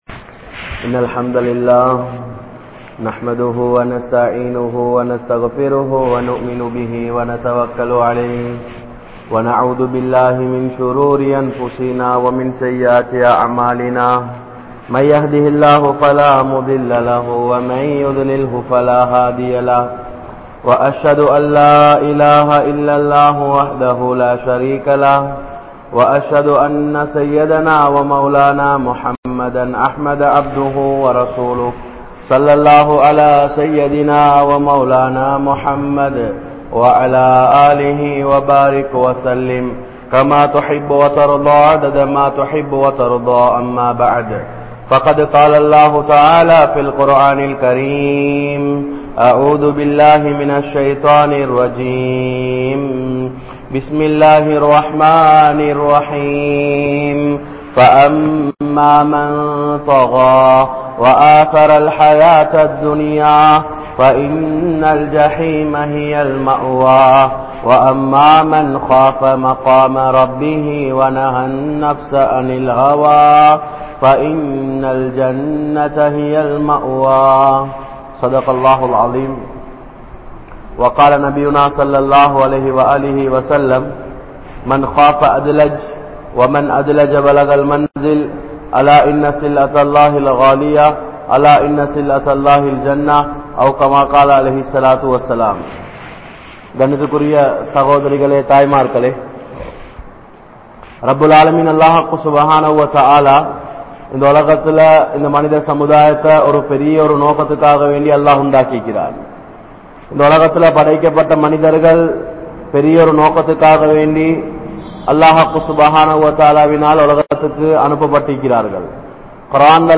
Islamiya Pengalin Panpuhal (இஸ்லாமிய பெண்களின் பண்புகள்) | Audio Bayans | All Ceylon Muslim Youth Community | Addalaichenai
Sammanthurai, Grand Jumua Masjidh